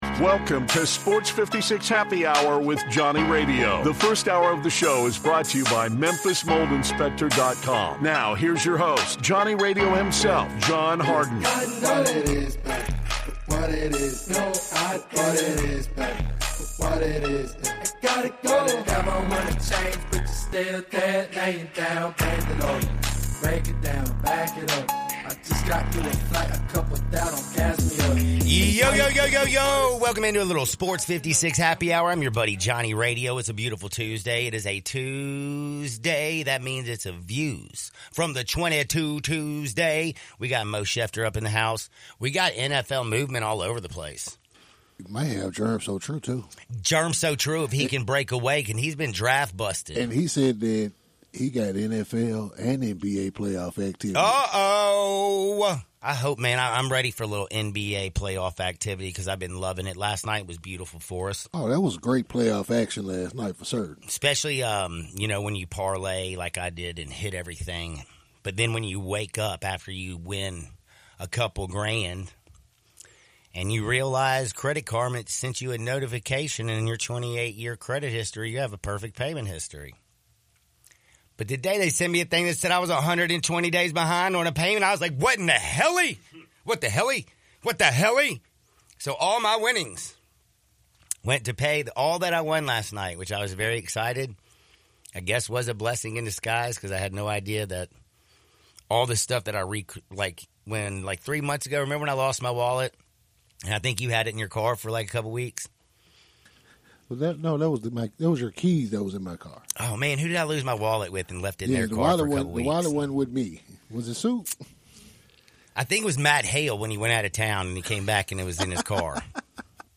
LIVE from the Family Leisure Studios